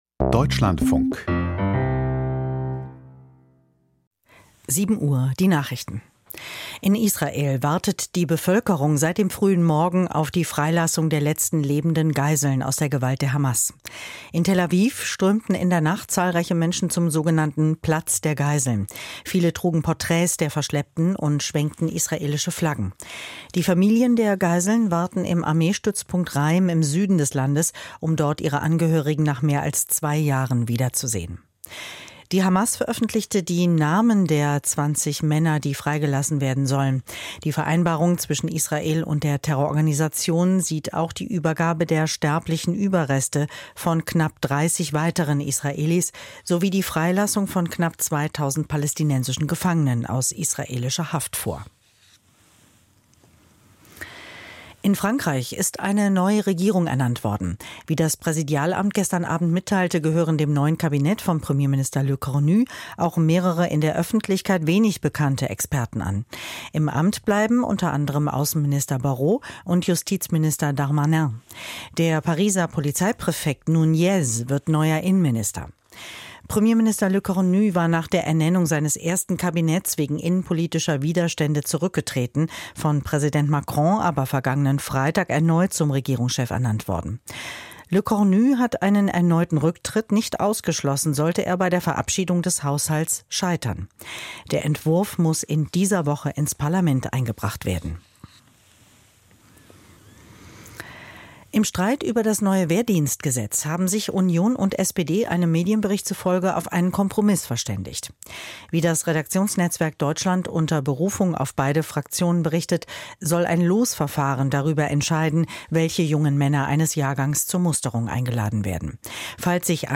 Die Nachrichten vom 13.10.2025, 07:00 Uhr